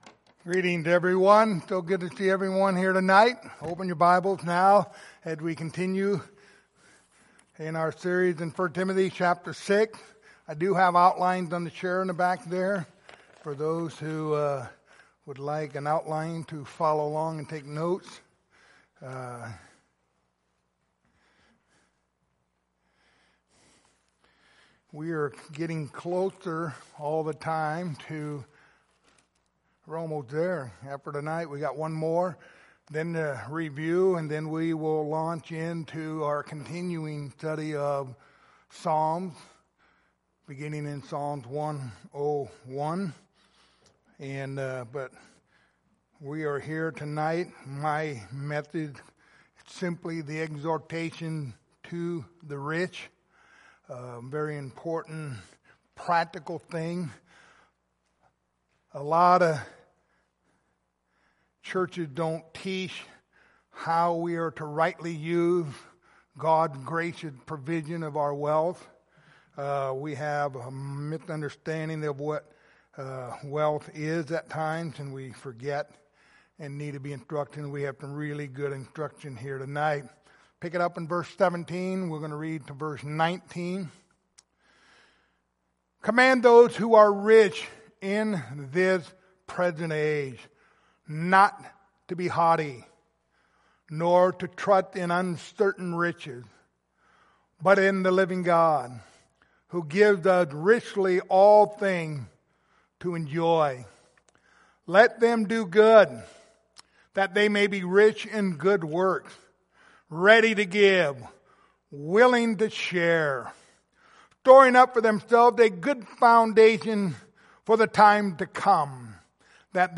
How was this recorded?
Passage: 1 Timothy 6:17-19 Service Type: Sunday Evening